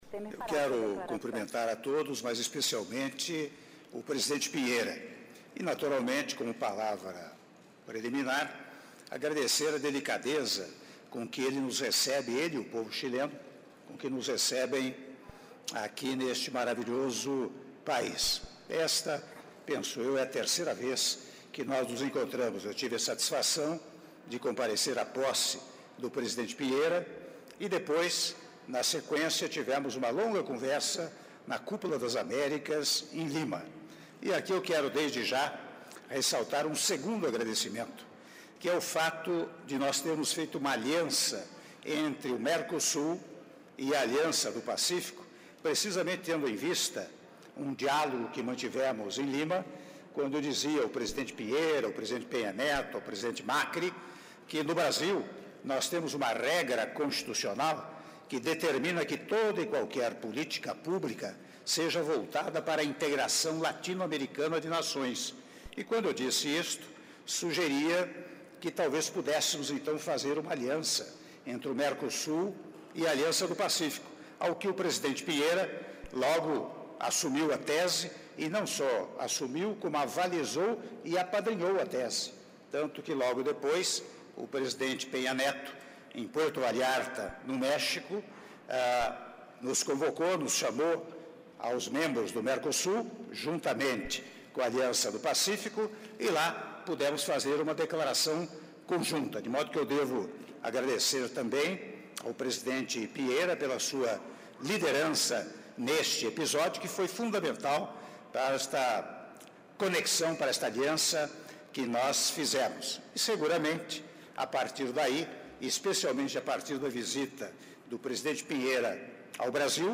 Áudio da declaração à imprensa do Presidente da República, Michel Temer, após cerimônia de assinatura do Acordo de Livre Comércio entre Brasil e Chile - Santiago/Chile (05min02s)